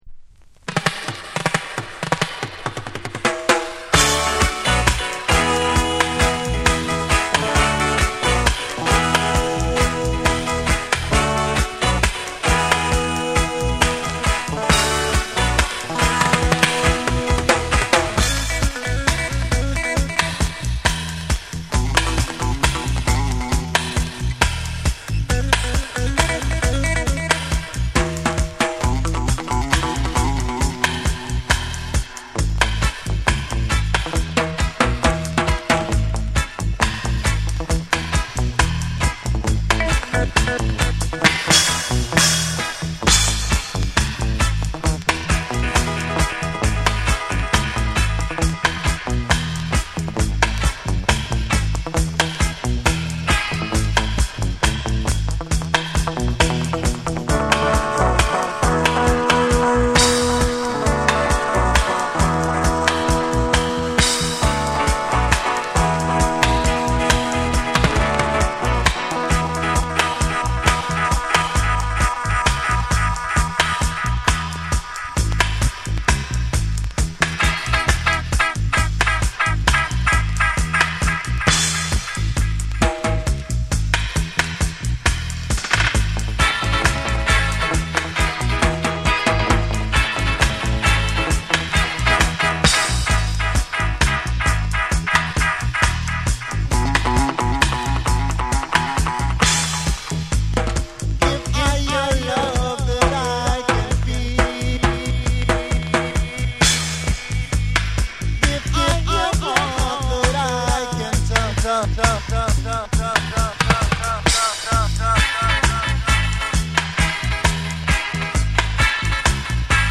タイトなリディムにエコーやリバーブを効かせた空間処理が冴え渡る。
REGGAE & DUB